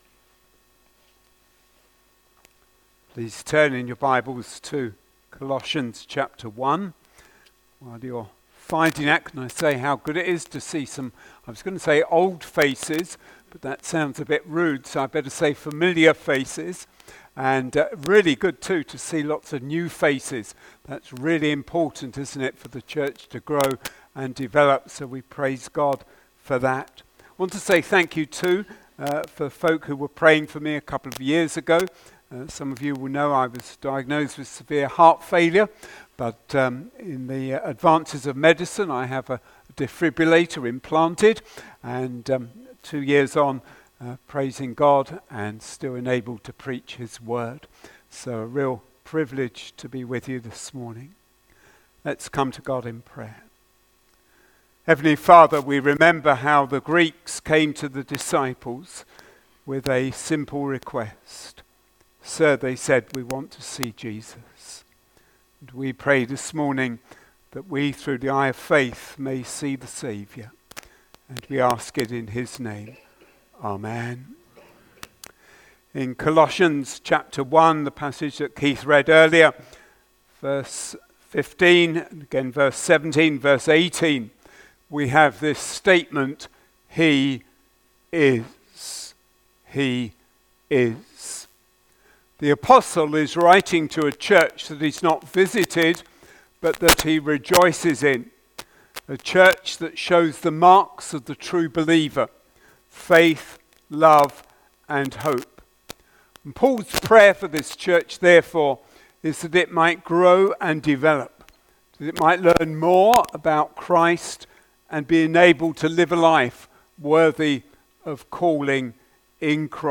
LABC Sermons Colossians 1v9-23 He is...